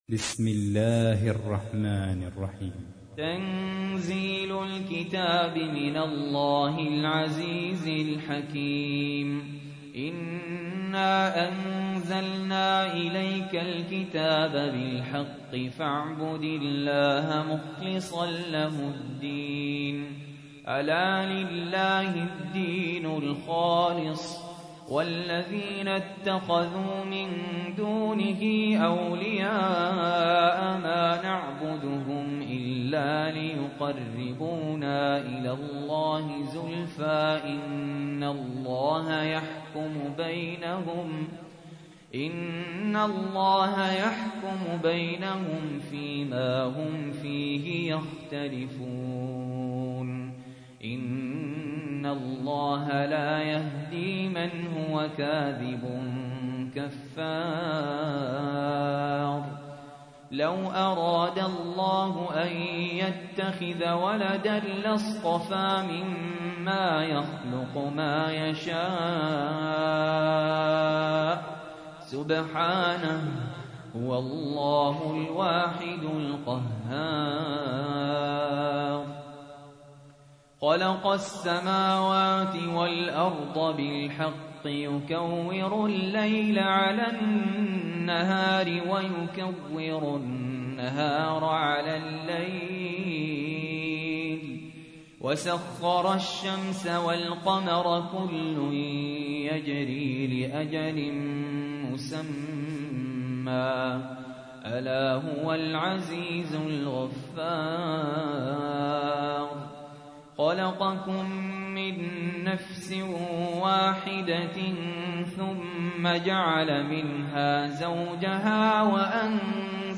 تحميل : 39. سورة الزمر / القارئ سهل ياسين / القرآن الكريم / موقع يا حسين